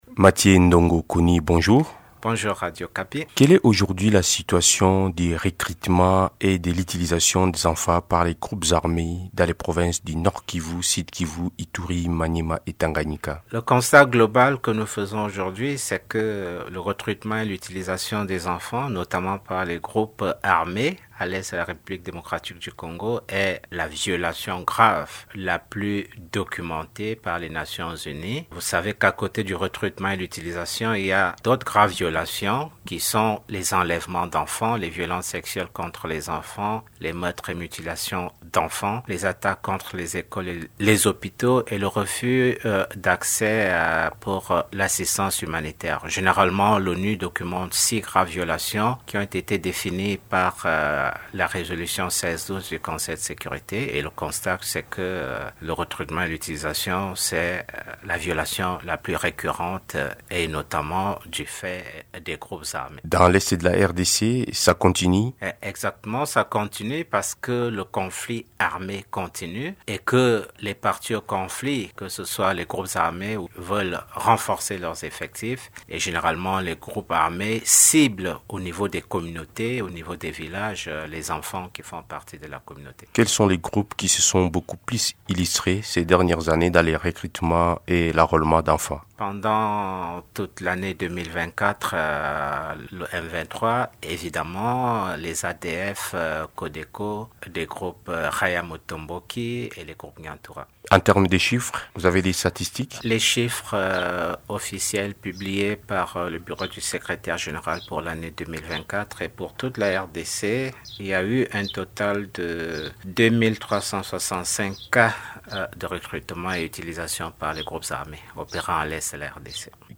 Invité de Radio Okapi